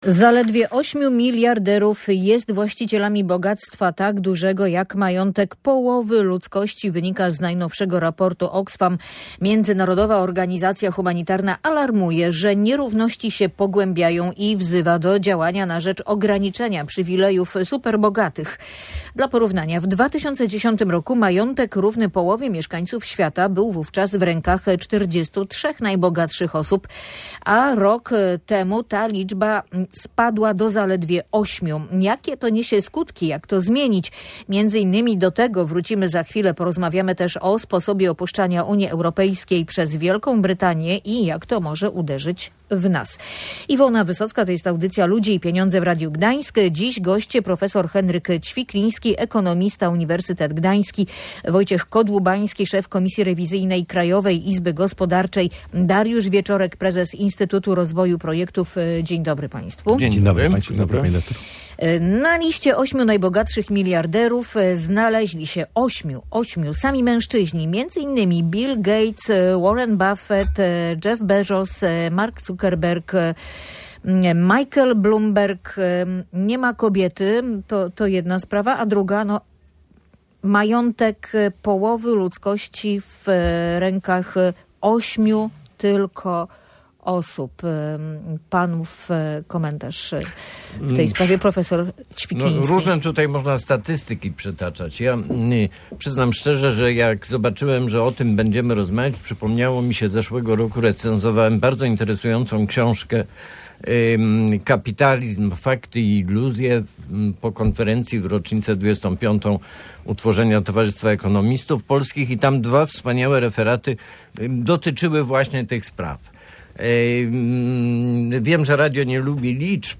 Zaledwie ośmiu miliarderów jest właścicielami bogactwa tak dużego, jak majątek połowy ludzkości – wynika z najnowszego raportu Oxfam. O niezwykłym bogactwie niektórych ludzi rozmawiali eksperci w audycji Ludzie i Pieniądze.